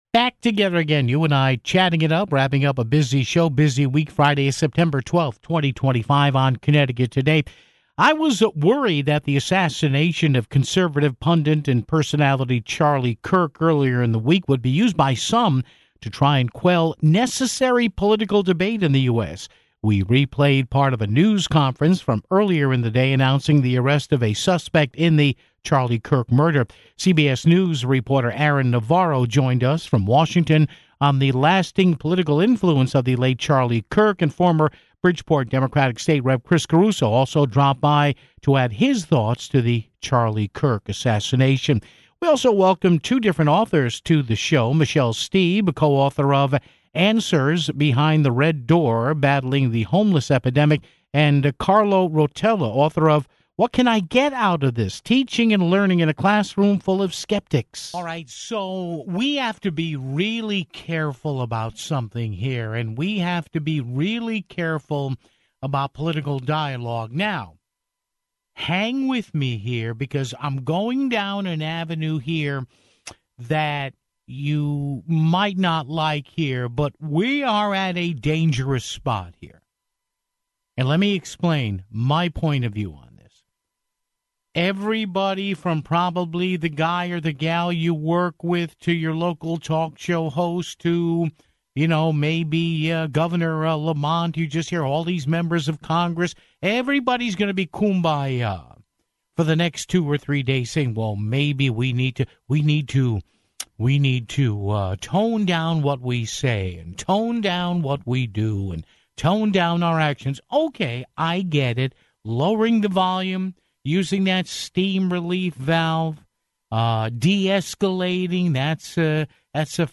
We replayed part of a news conference from earlier in the day announcing the arrest of a suspect in Charlie Kirk's murder (17:00).